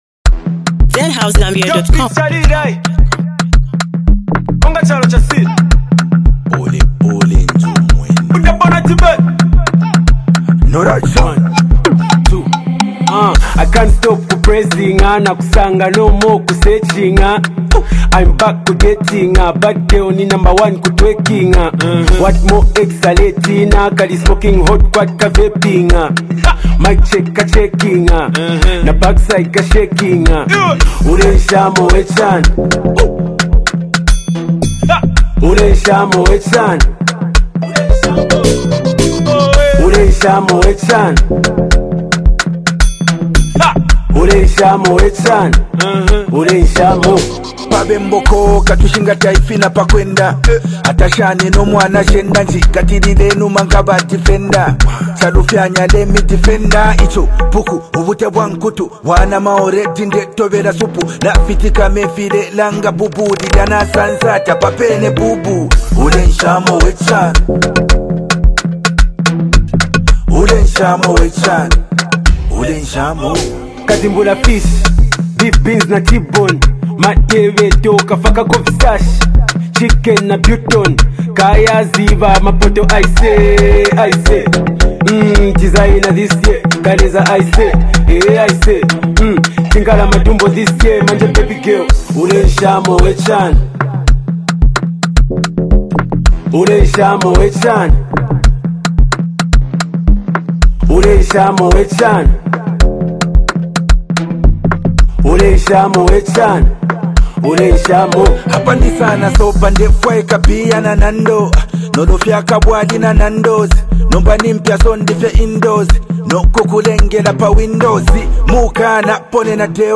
powerful anthem